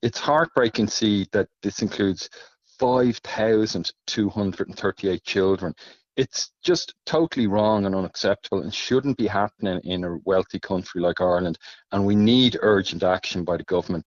Spokesperson